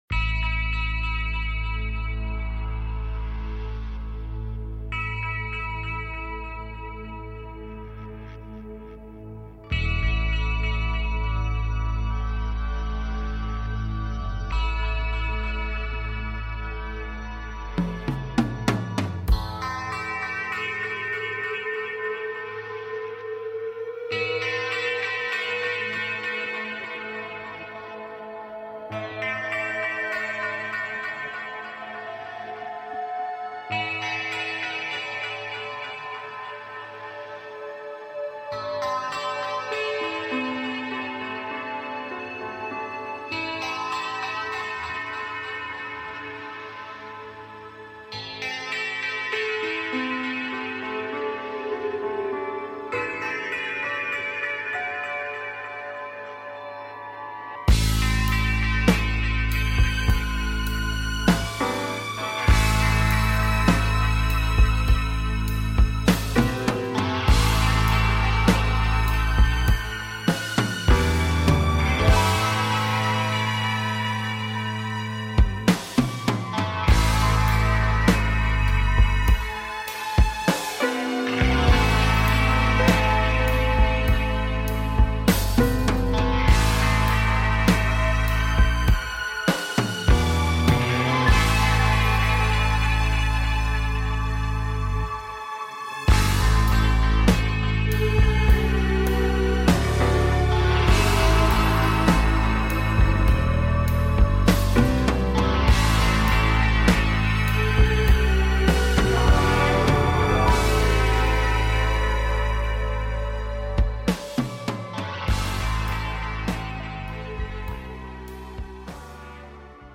Talk Show Episode
Reviewing listener projects and answering listener calls